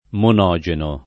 monogeno